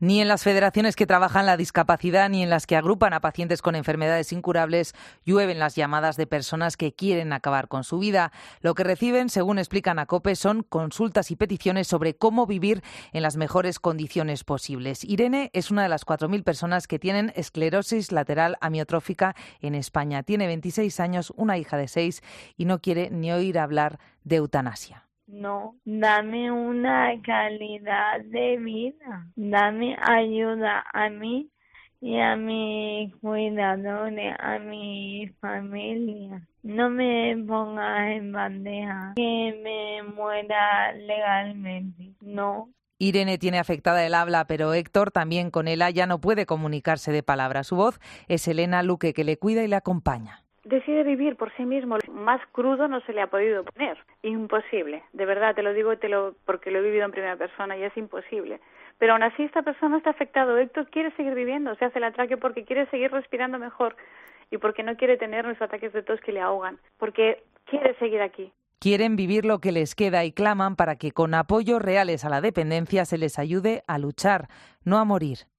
AUDIO: Un reportaje